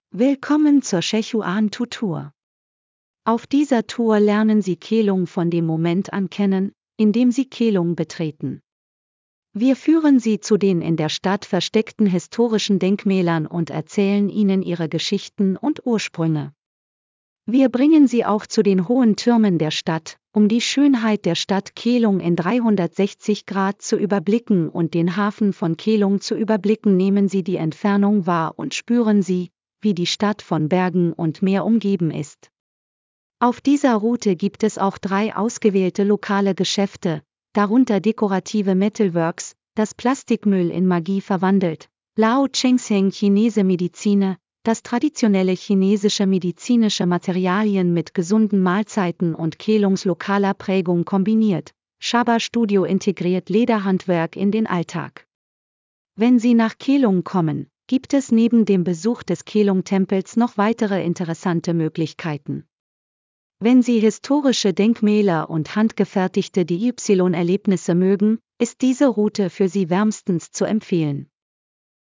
Einminütige kostenlose Probe der Audioführung dieser Strecke